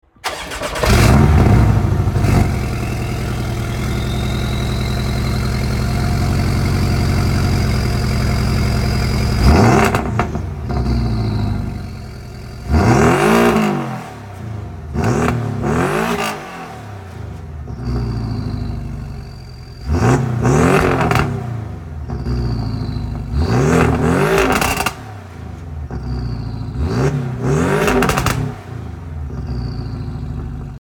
Écoutez le son du moteur !
porshe-718.mp3